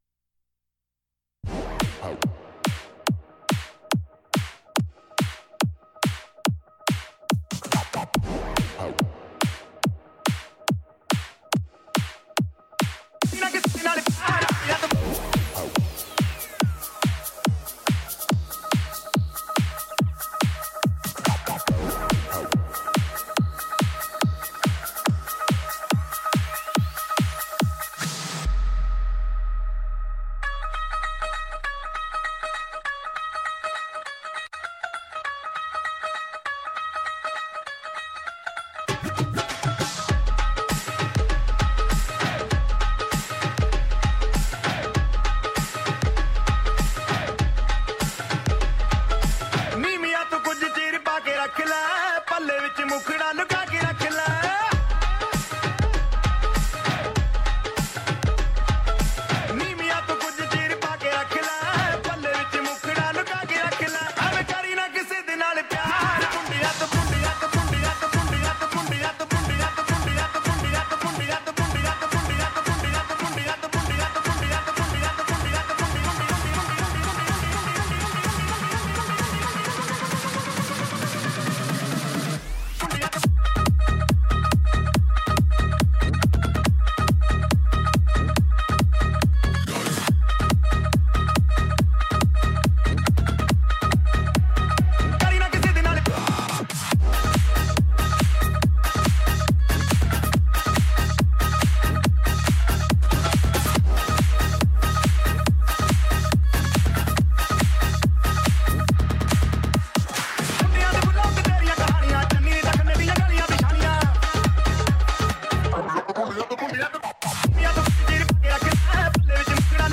der er en blandning af hansup og lidt 80er og 90er